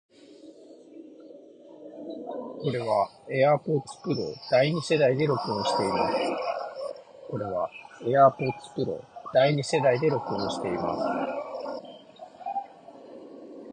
スピーカーから雑踏音をそこそこ大きなボリュームで流しながら収録しています。
そしてこちらはAirPods Pro（第2世代）で録音した音声。
音声はAirPods Pro（第2世代）の方がクリアですが、周囲の雑音はOpera 05の方がよく消えてるように感じます。
opera05-airpods.m4a